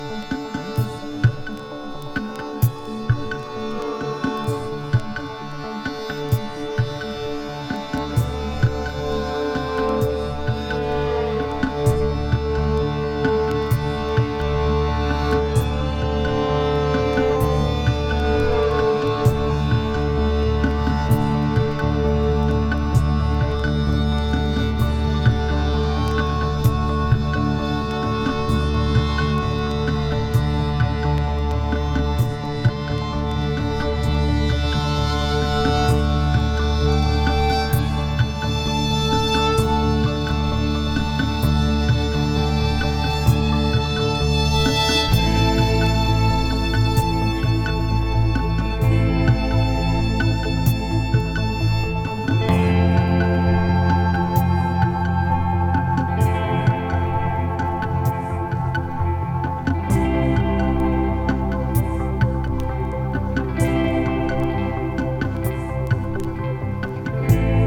サイケデリック・シンセ・ポストロック・バンド